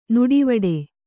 ♪ nuḍivaḍe